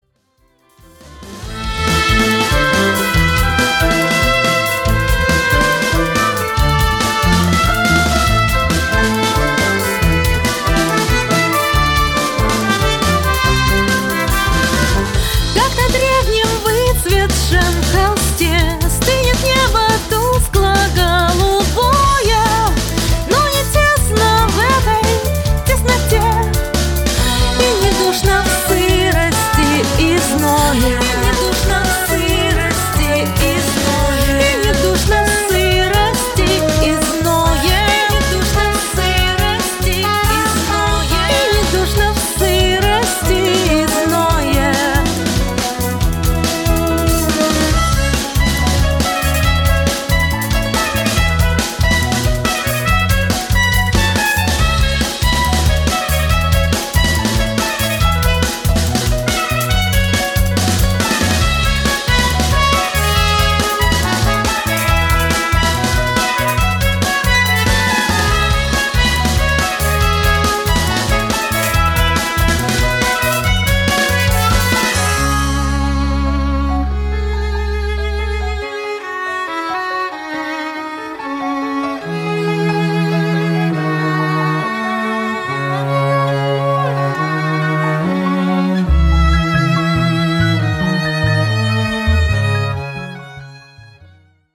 voice
violin
cello